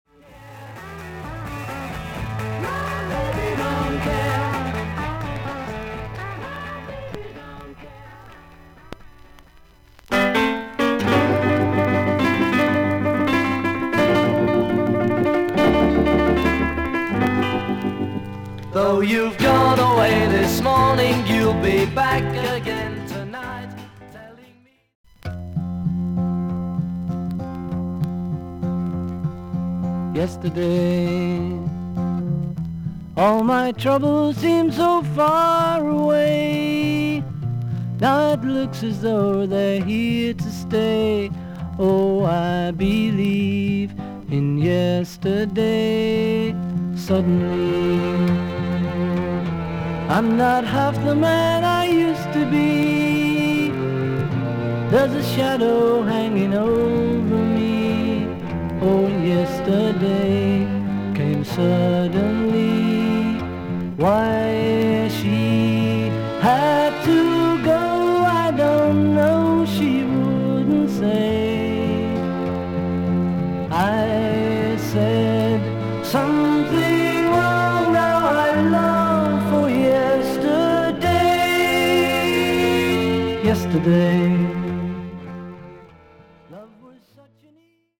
ほかはVG++:少々軽いパチノイズの箇所あり。クリアな音です。